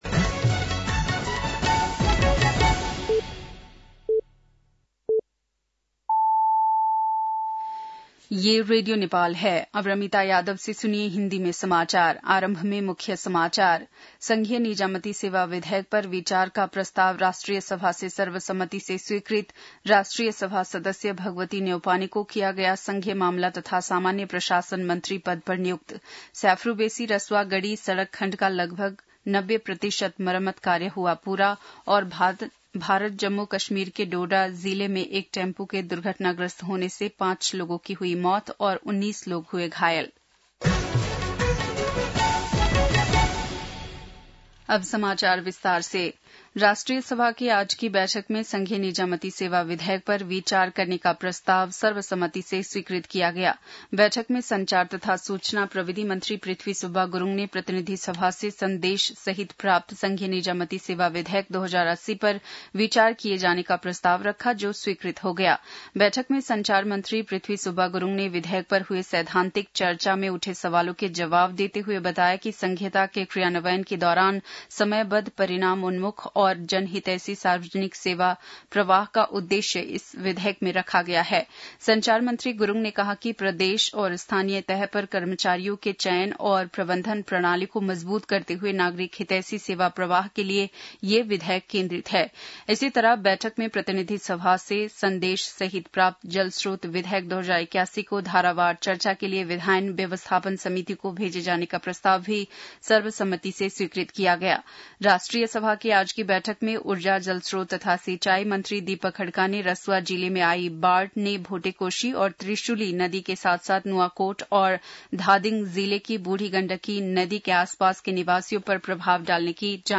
बेलुकी १० बजेको हिन्दी समाचार : ३१ असार , २०८२
10-pm-hindi-news-3-31.mp3